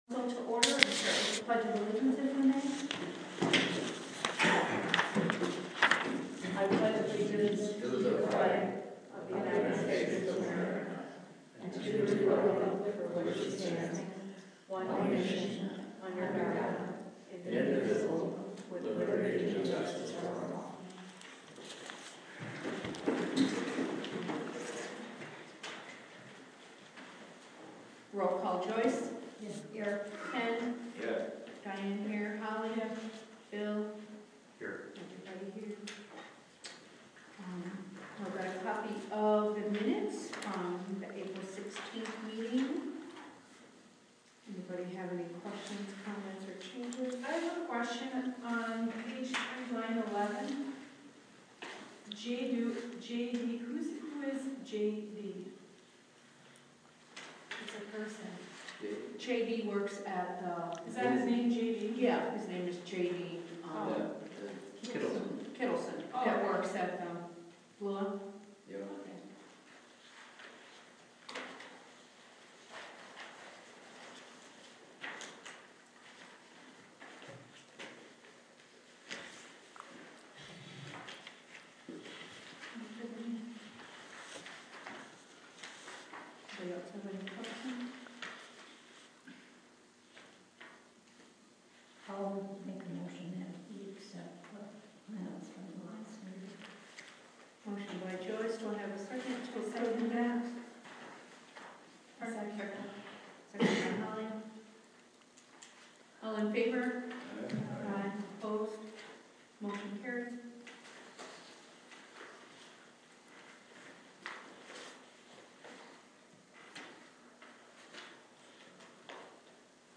VILLAGE OF ELBERTA BOARD OF TRUSTEES REGULAR MEETING